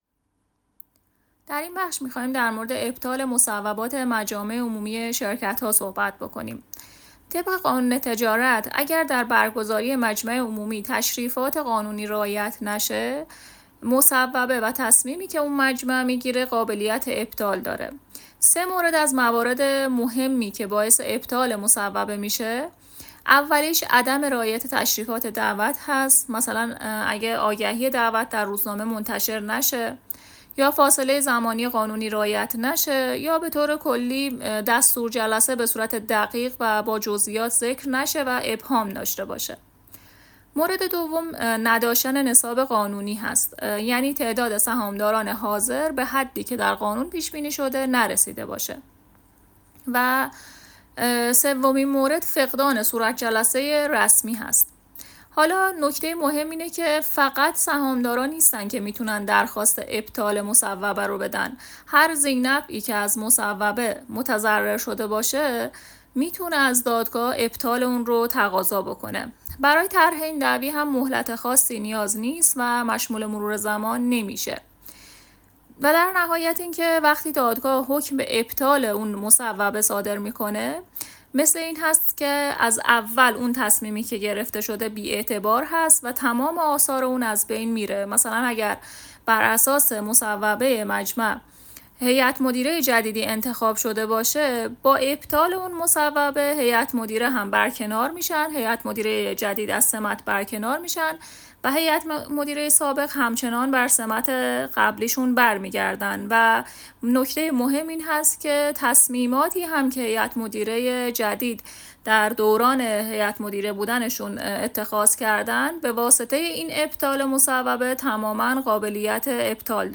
ویس پاسخ به سوال: